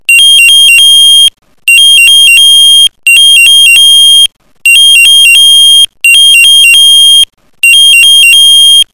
DME_-_Einsatz_lang.mp3